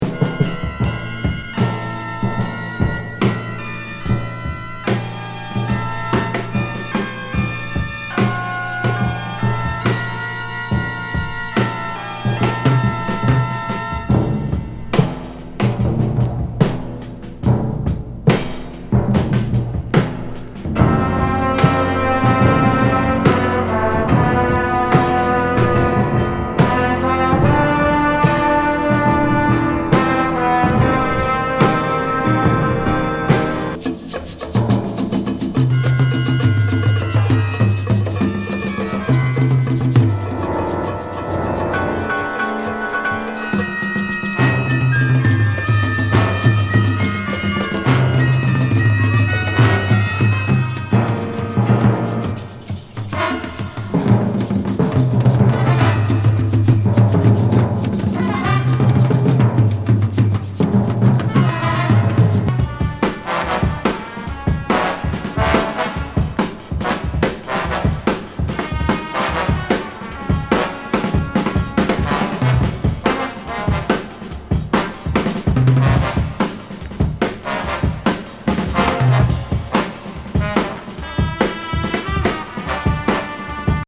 不穏電子サイケ・ブレイキン・グルーブ